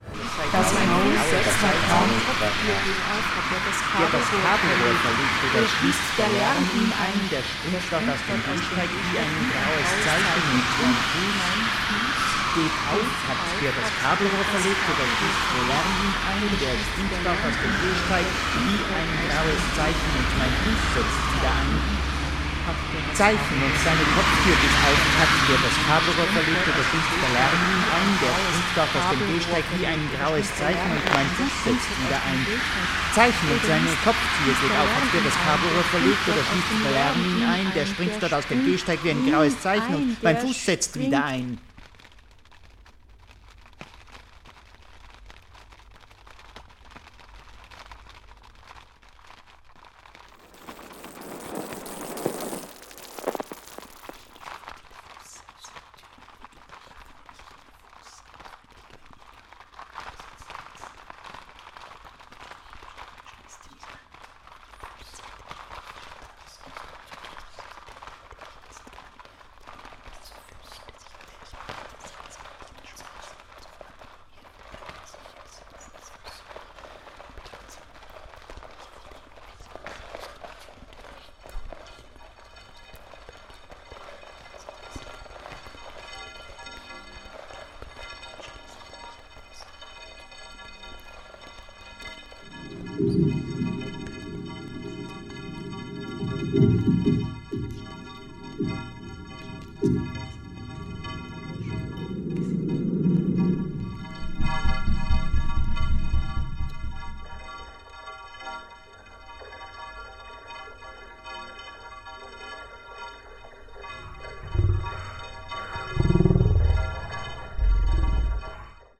piano
voice
trpt
tuba